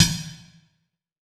SNARE 021.wav